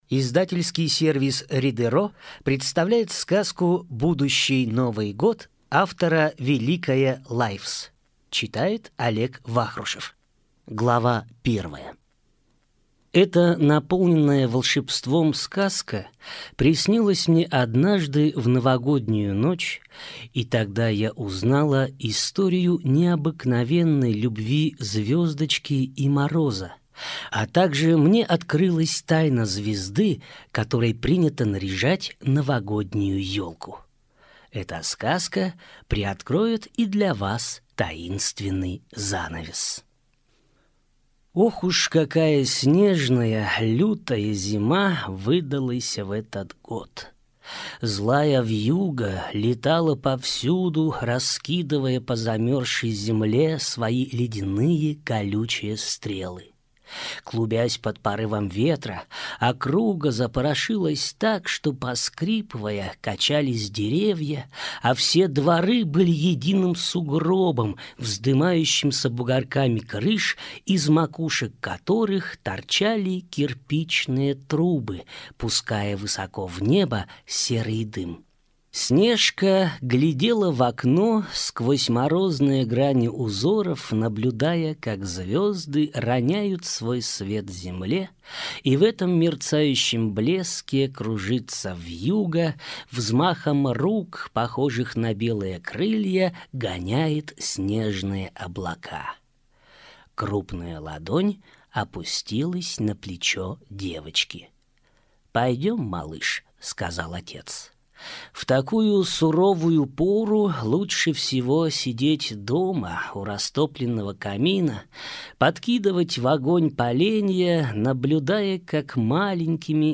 Аудиокнига Будущий новый год | Библиотека аудиокниг